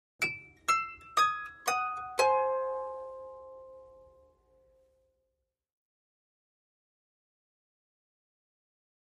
Harp, Descending Arpeggio In Two Voices, Type 1